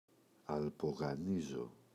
αλπογανίζω [alpoγaꞋnizo]
αλ’πογανίζω.mp3